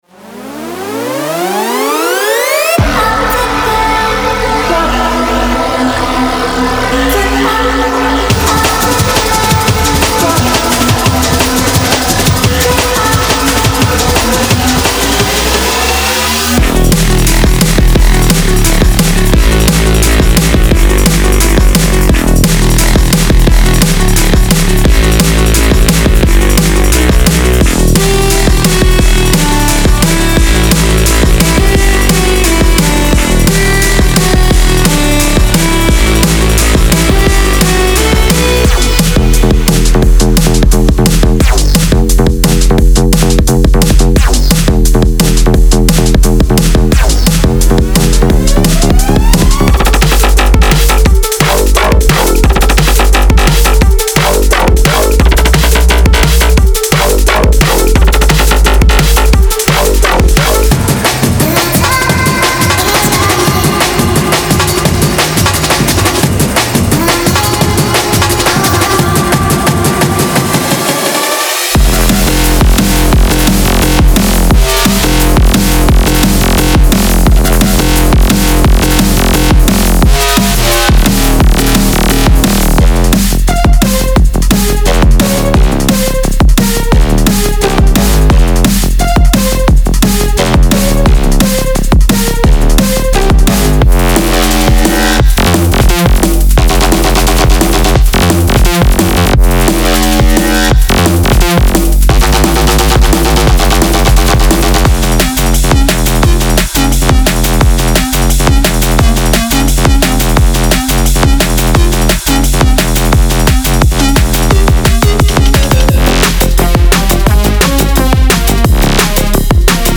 Genre:Drum and Bass
デモサウンドはコチラ↓
174 BPM